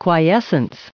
added pronounciation and merriam webster audio
1013_quiescence.ogg